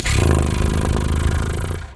cat_2_purr_06.wav